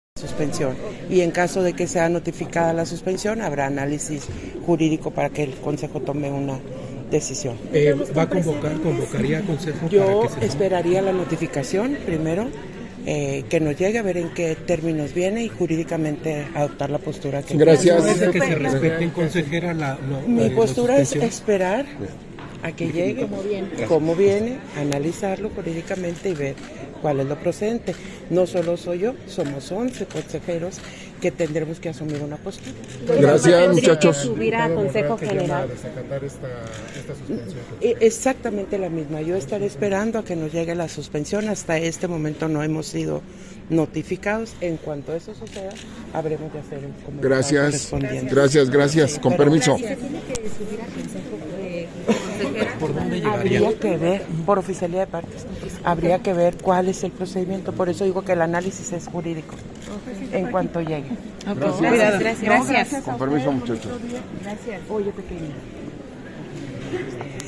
260924_AUDIO_ENTREVISTA-CONSEJERA-PDTA.-TADDEI-SESION-ORDINARIA - Central Electoral